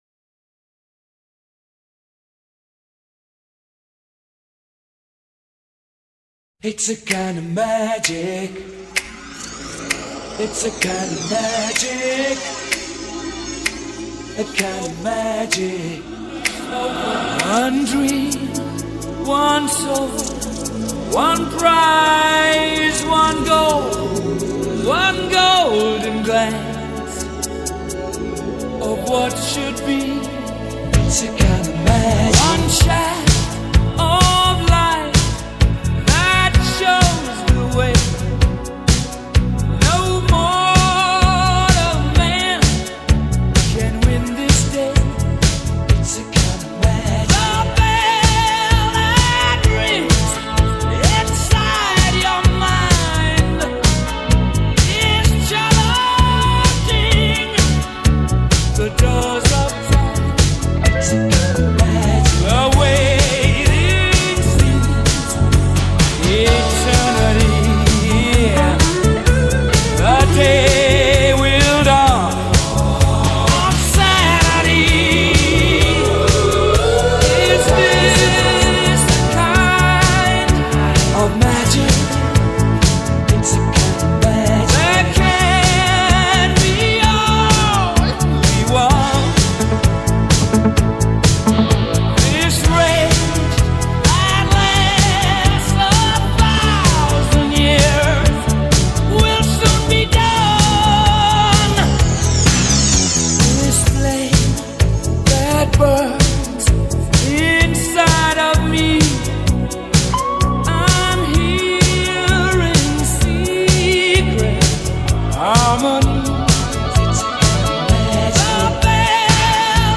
Rock, Pop Rock